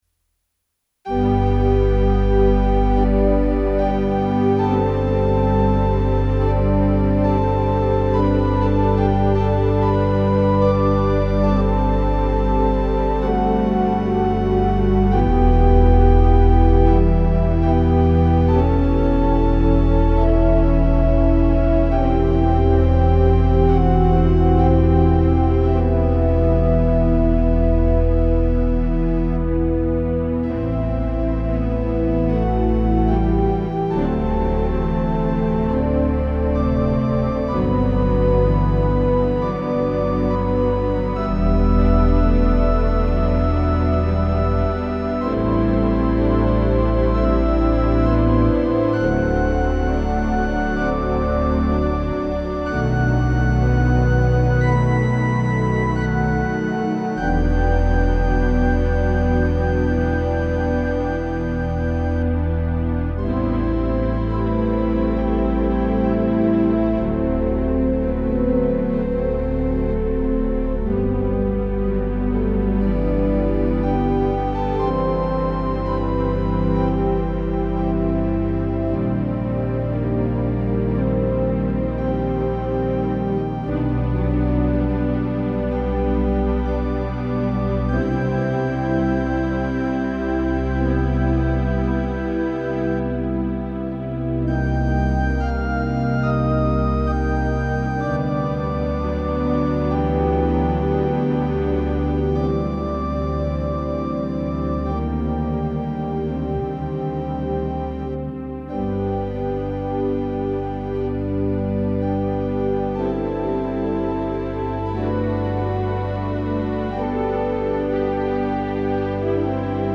St. James Memorial Episcopal Church
1909 Austin Organ, Opus 246